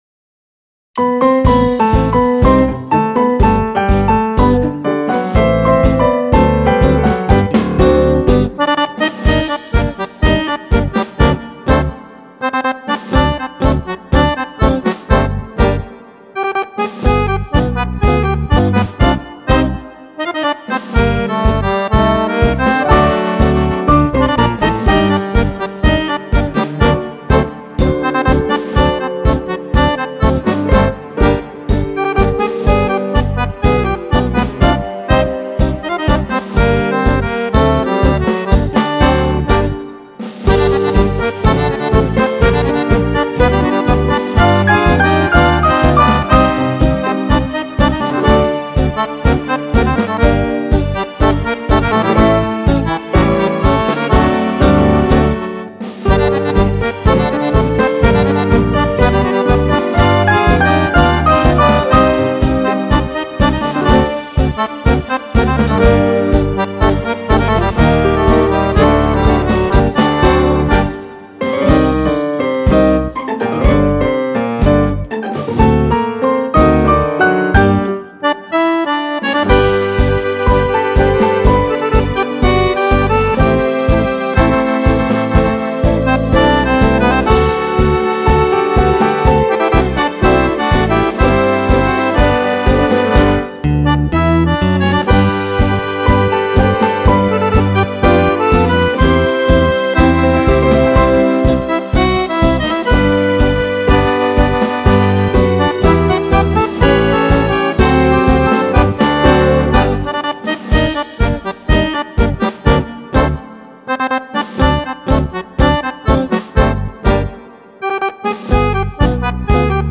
Tango
10 BALLABILI PER FISARMONICA